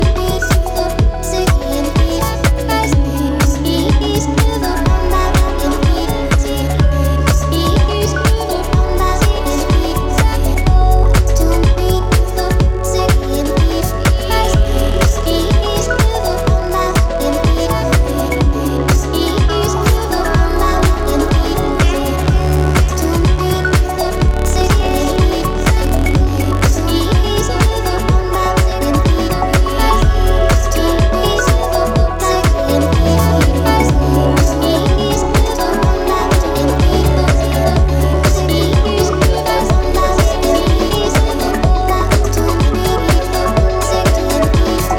die einige der härtesten und tanzbarsten Grooves enthalten